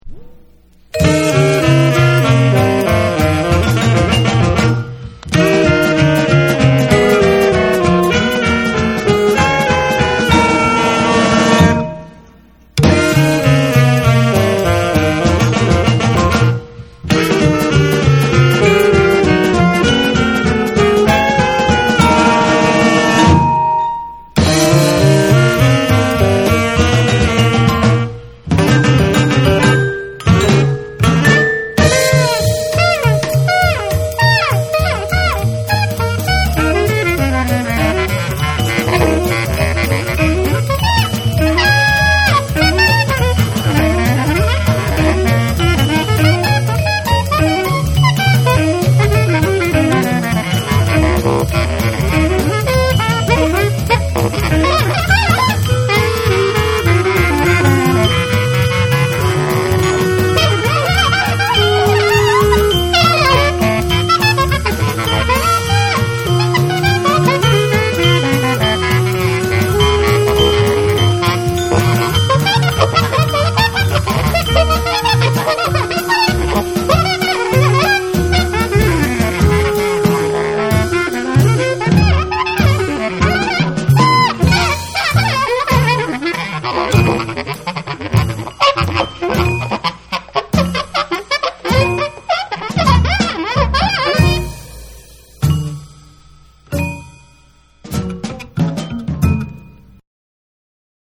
シカゴの音響系ガレージ・バンド
ジャズ〜モンド〜エキゾが交差したストレンジ・サウンド